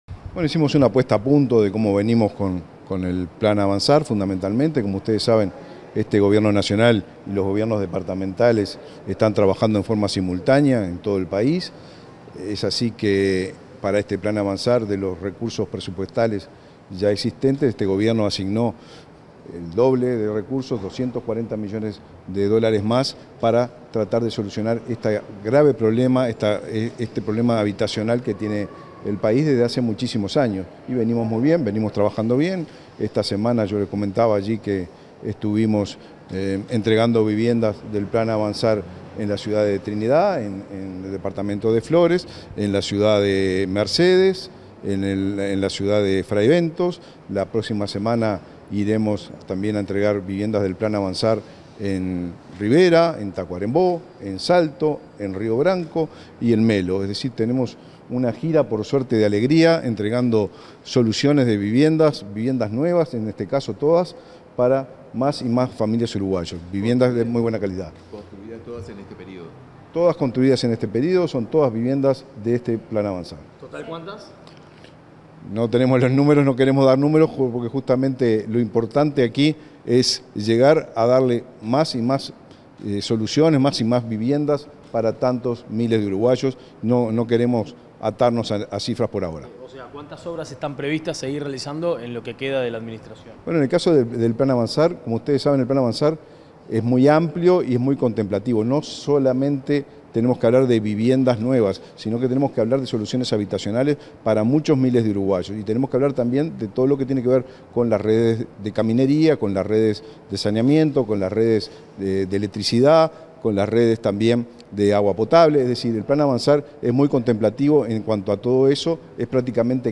Declaraciones a la prensa del ministro de Vivienda, Raúl Lozano, y del intendente de Maldonado, Enrique Antía
Declaraciones a la prensa del ministro de Vivienda, Raúl Lozano, y del intendente de Maldonado, Enrique Antía 15/12/2023 Compartir Facebook X Copiar enlace WhatsApp LinkedIn Tras una nueva reunión de la Comisión de Seguimiento del Plan Avanzar, el ministro de Vivienda, Raúl Lozano, y el intendente de Maldonado, Enrique Antía, realizaron declaraciones a la prensa.